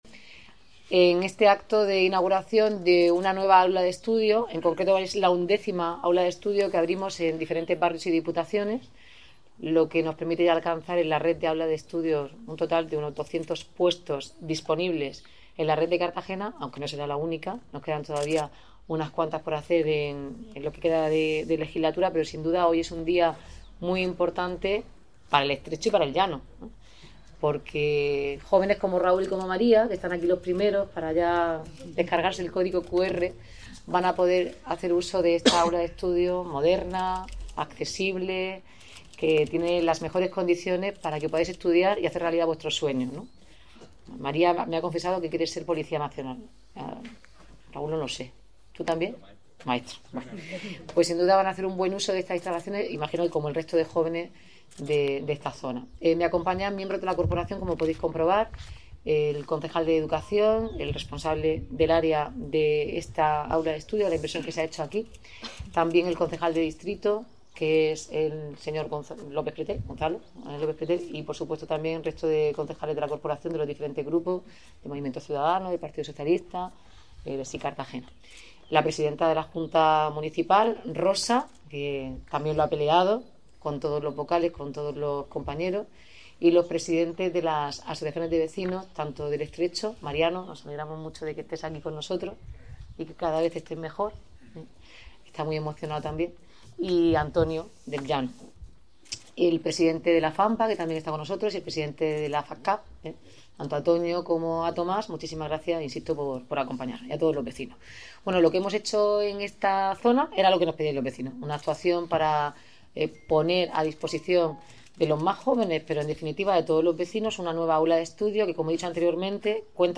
El Estrecho de San Ginés dispone desde este lunes 3 de noviembre de un nuevo servicio público de estudio abierto las 24 horas que supera los 200 puestos disponibles en todo el municipio, según ha destacado la alcaldesa, Noelia Arroyo, durante la inauguración de las nuevas instalaciones, que se integran en la Red Municipal de Aulas de Estudio del Ayuntamiento de Cartagena.
Inauguración del nuevo Aula de Estudio de El Estrecho de San Ginés